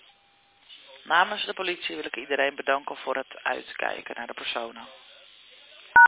Burgernet heeft een audioboodschap ingesproken bij deze melding.